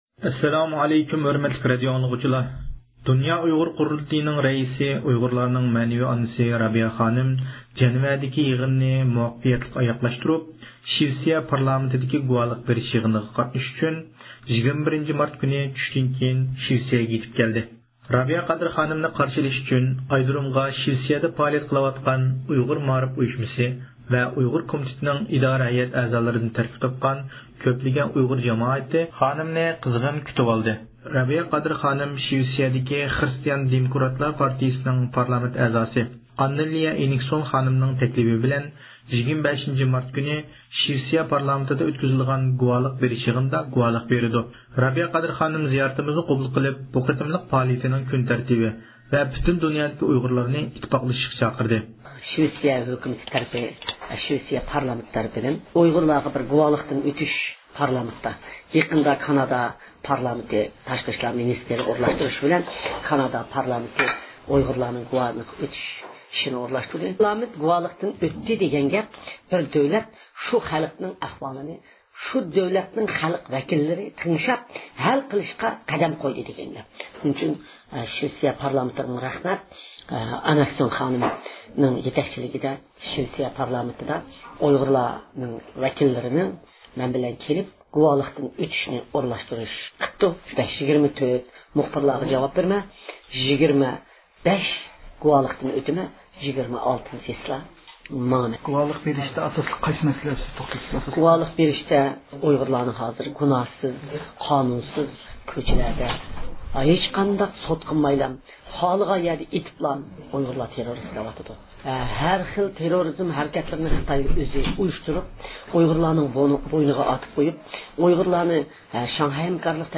رابىيە قادىر خانىم زىيارىتىمىزنى قوبۇل قىلىپ، بۇ قېتىملىق پائالىيىتىنىڭ كۈنتەرتىپى ھەققىدە مەلۇمات بەردى ۋە پۈتۈن دۇنيادىكى ئۇيغۇرلارنى ئتتىپاقلىشىشقا چاقىردى.